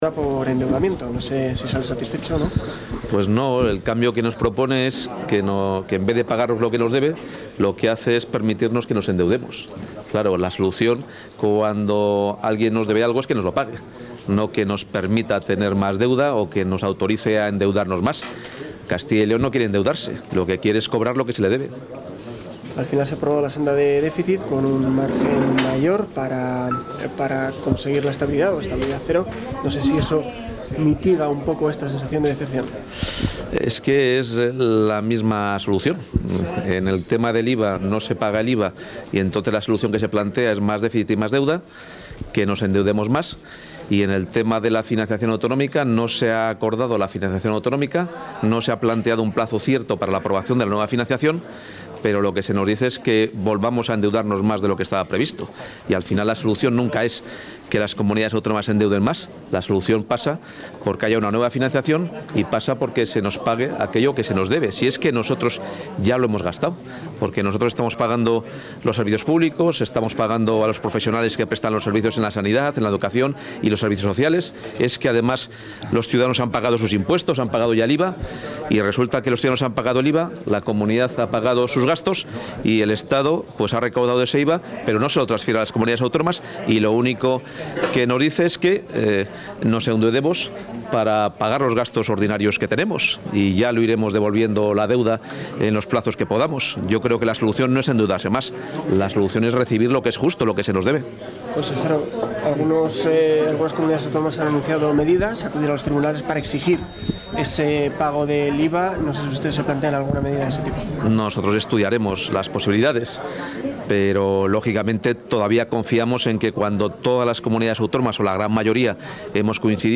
Declaraciones del consejero de Economía y Hacienda tras asistir al Consejo de Política Fiscal y Financiera